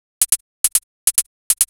Techno / Drum / HIHAT026_TEKNO_140_X_SC2.wav